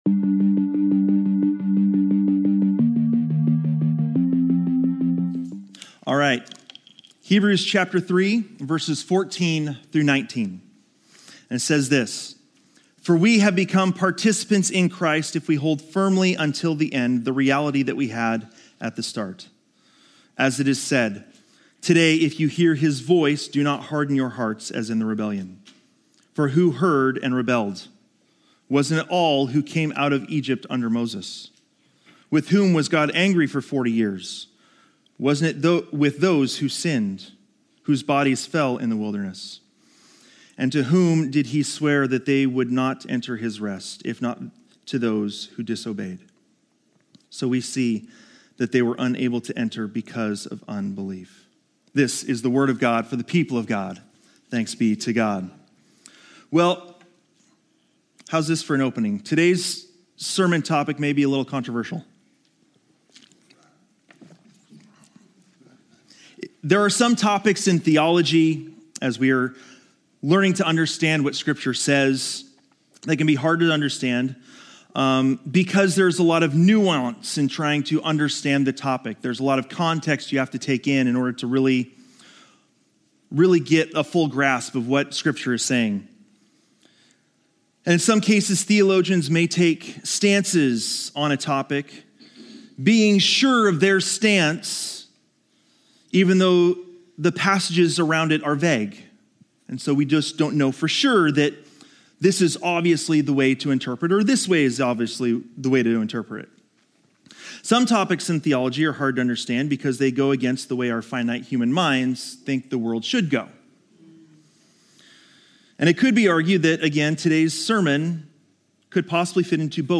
This sermon examines the paradox of human responsibility and God's sovereignty, drawing insights from theological giants like Charles Spurgeon, Martin Luther, and Sinclair Ferguson. Learn why encouragement within the Christian community is vital for maintaining strong faith and avoiding the hardening of hearts through sin's deception.